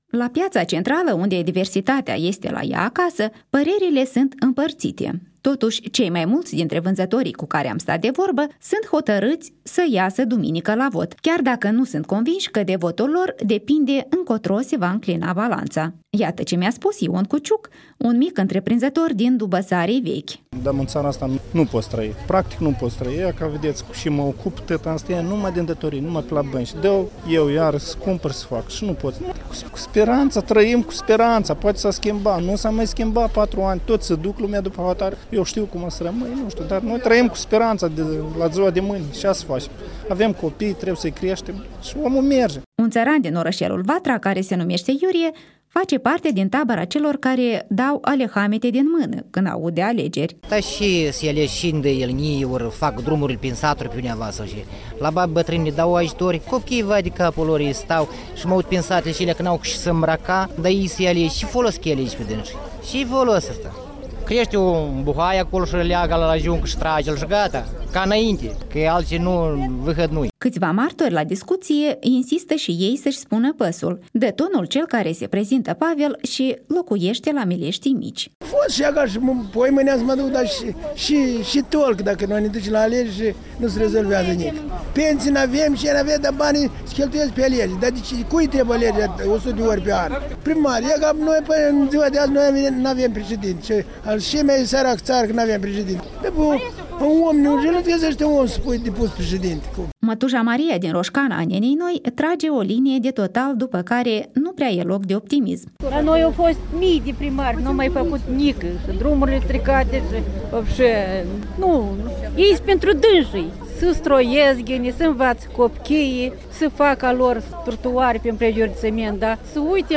Cu microfonul Europei Libere la Piaţa centrală din Chişinău